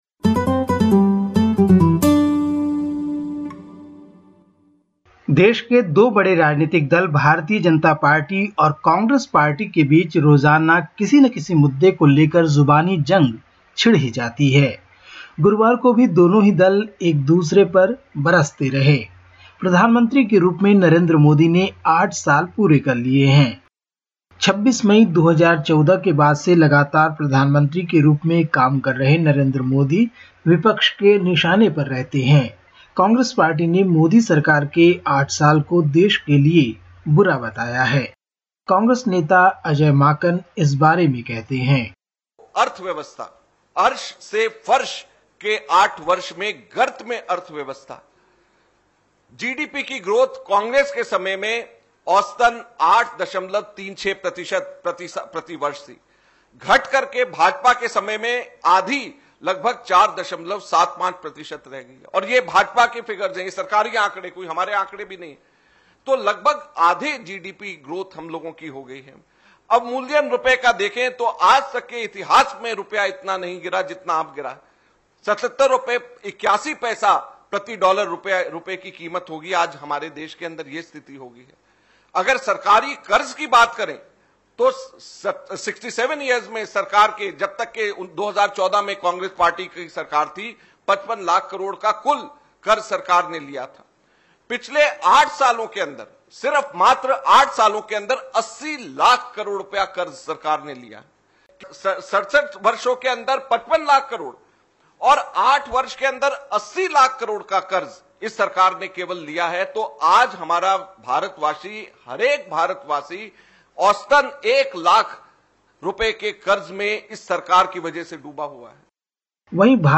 Listen to the latest SBS Hindi report from India. 27/05/2022